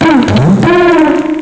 pokeemerald / sound / direct_sound_samples / cries / garbodor.aif